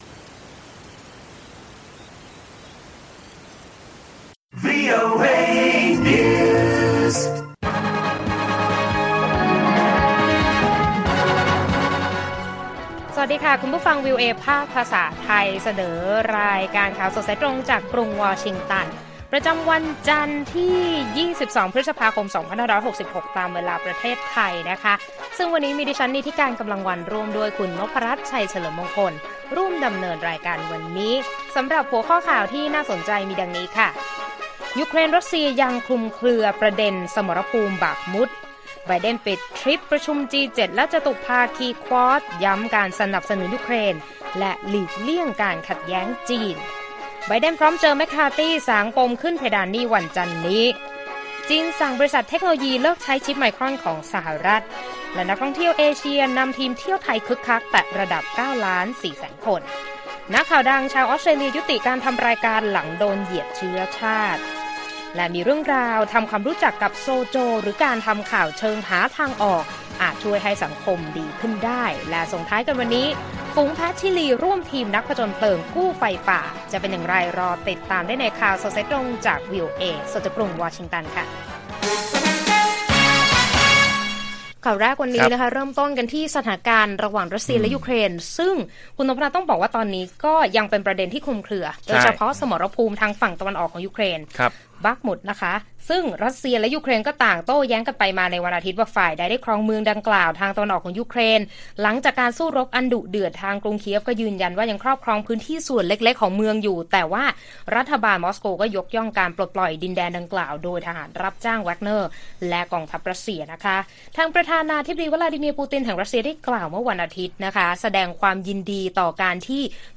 ข่าวสดสายตรงกับวีโอเอไทย 6:30 – 7:00 น. วันที่ 22 พ.ค. 2566